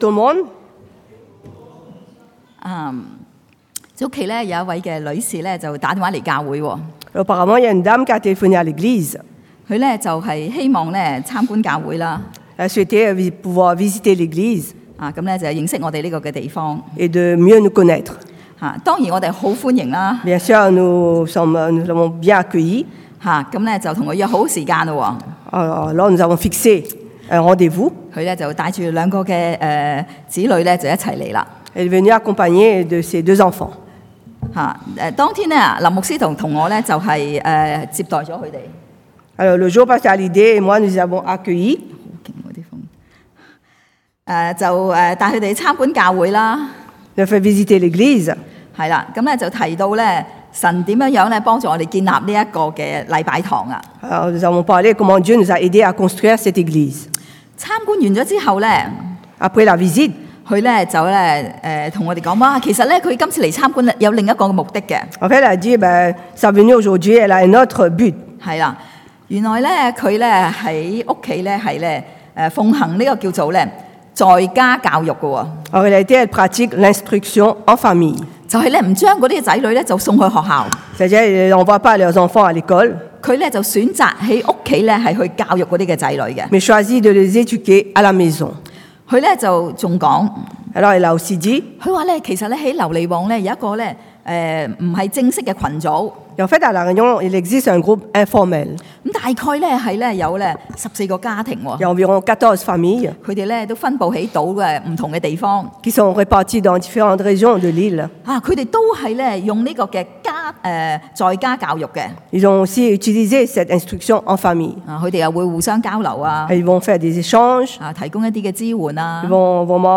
Passage: Josué 约书亚记 4 : 10-24 Type De Service: Predication du dimanche « Guilgal